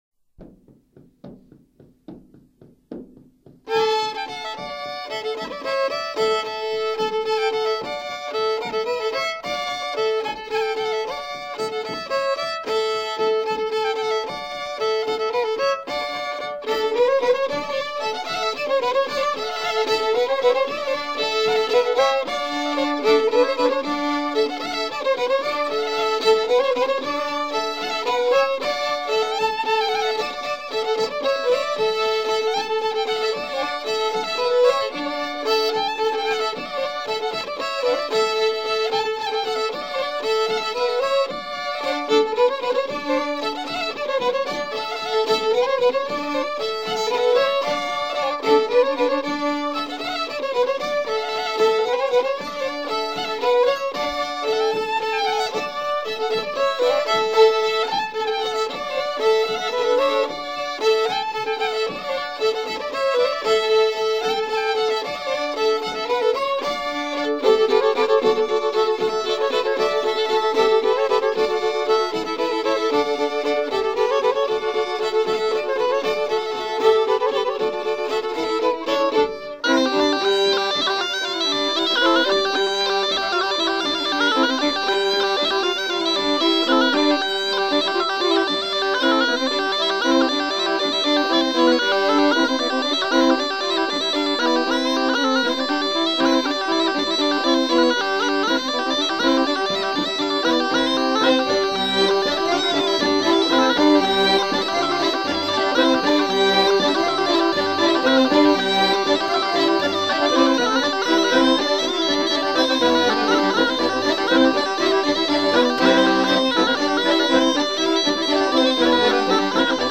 Deux bourrées recueillies
danse : bourree